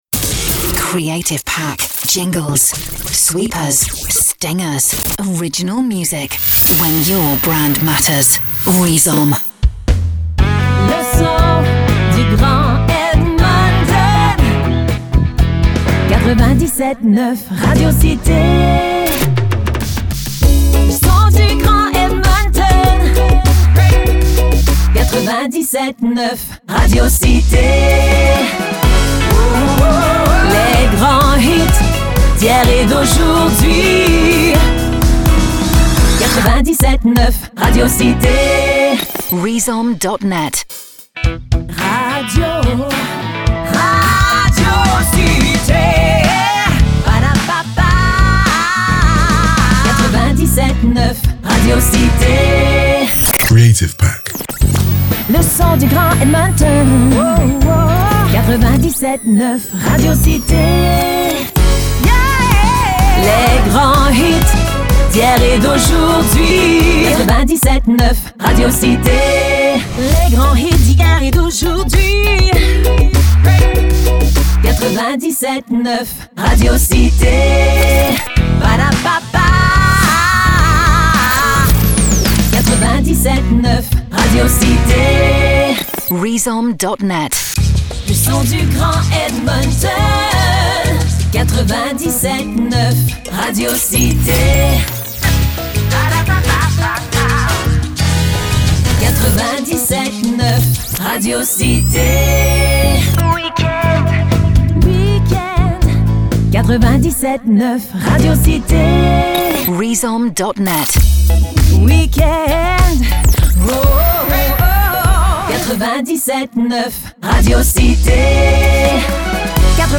On air jingles crafted by our radio producers team.